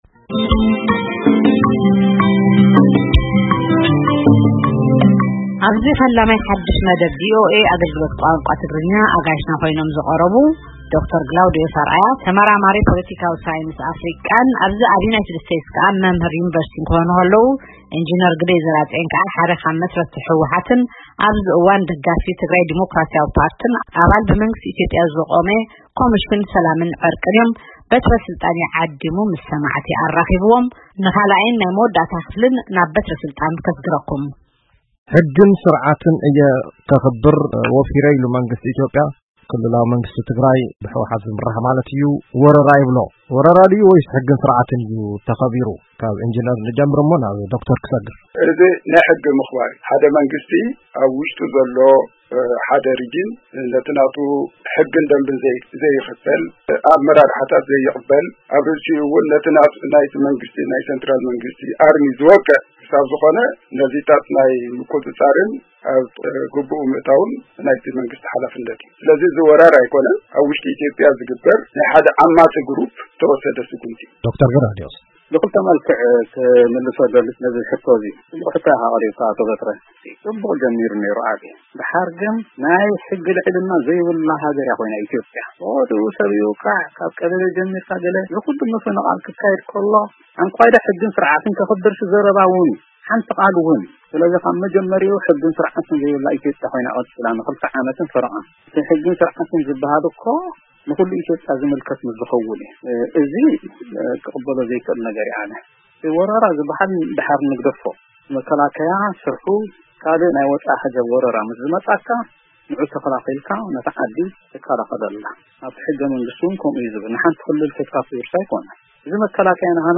ክትዕ